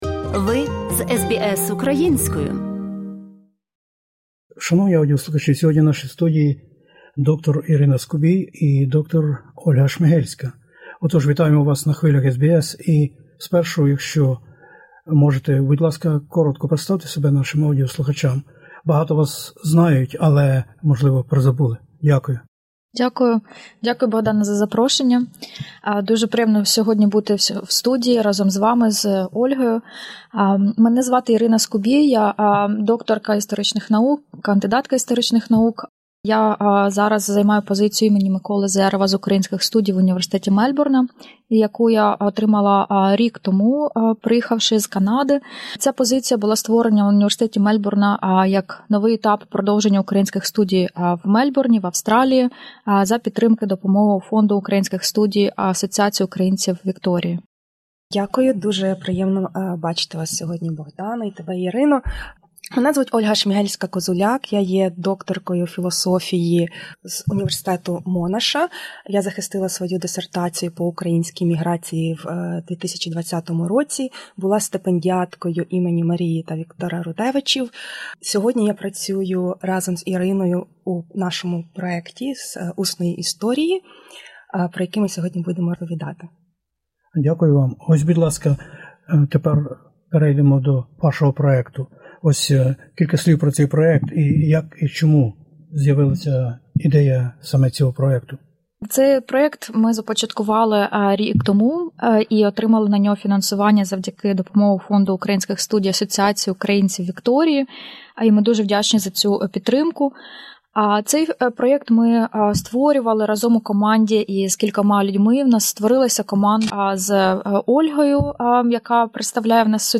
у розмові SBS Ukrainian